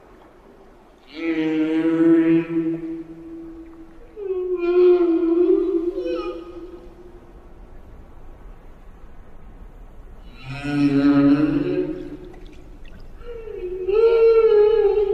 whale-mating-song.mp3